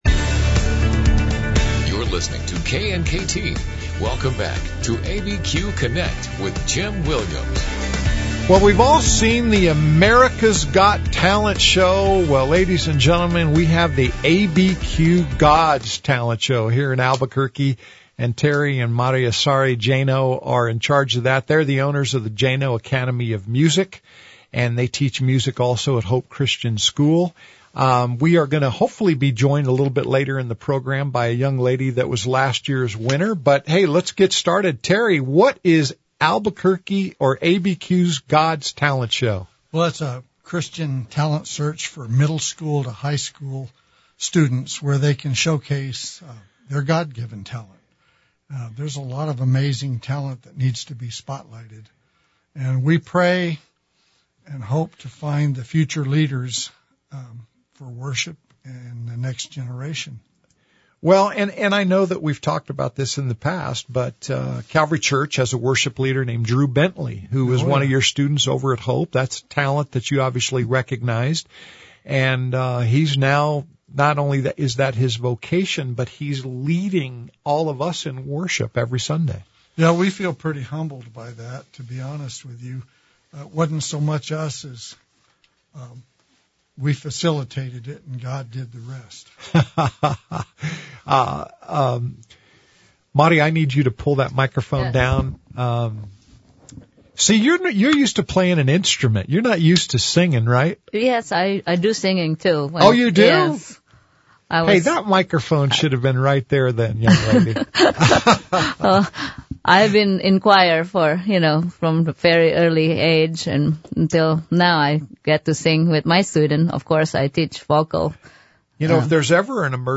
Albuquerque's live and local call-in show.